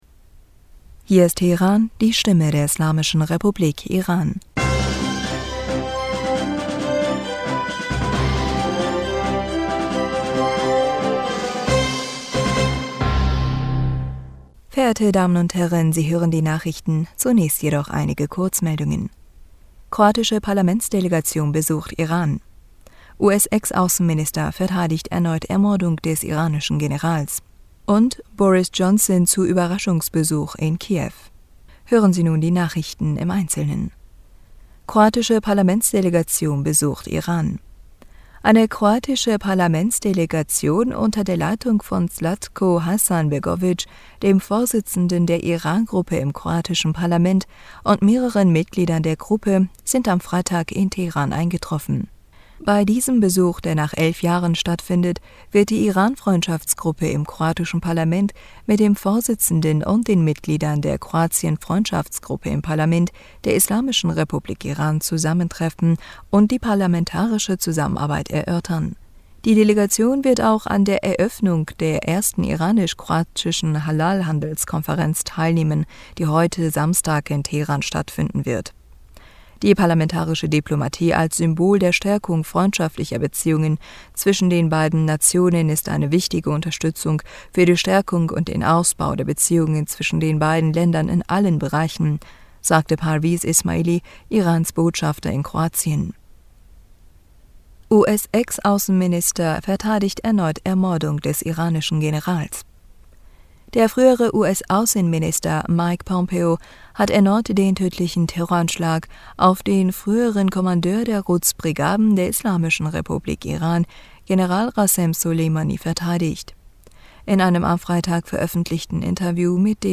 Nachrichten vom 18. Juni 2022